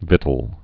(vĭtəl)